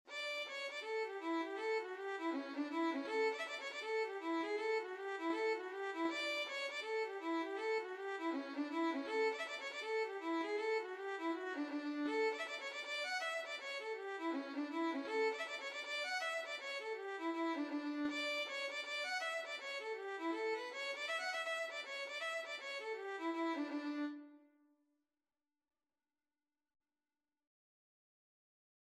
Free Sheet music for Violin
Db5-F#6
D major (Sounding Pitch) (View more D major Music for Violin )
4/4 (View more 4/4 Music)
Reels
Irish